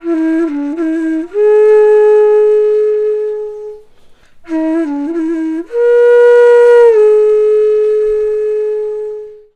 FLUTE-A03 -L.wav